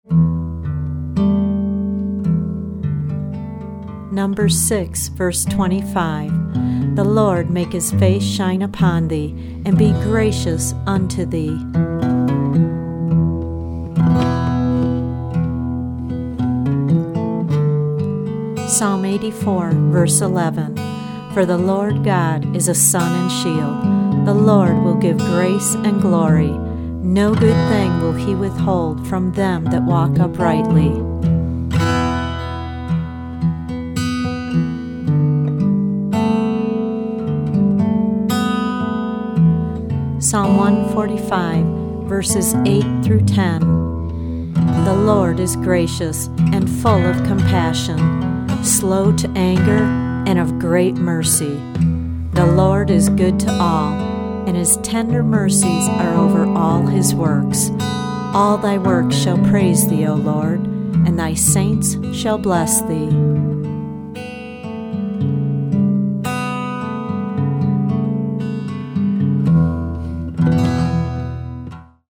original anointed instrumental music on six CD’s.